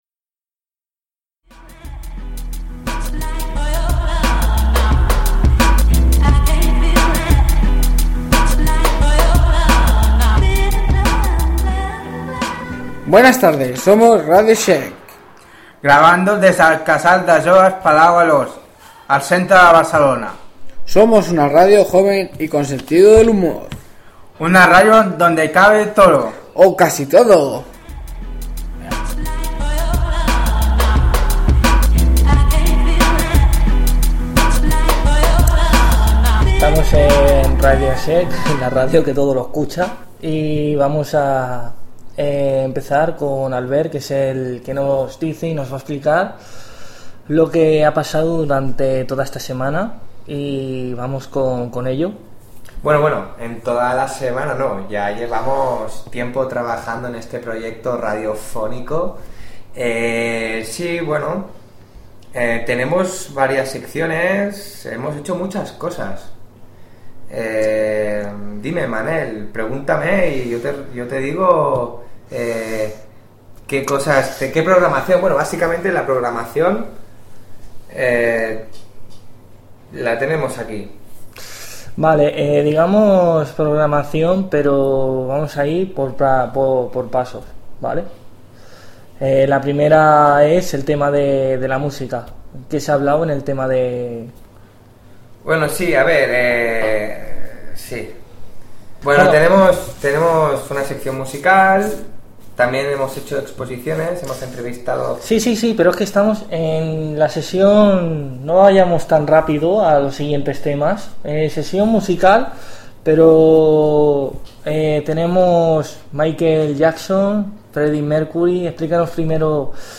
Infantil-juvenil
Primer programa fet pels joves de l'Espai Jove del Palau Alós del carrer Sant Pere més Baix de Barcelona.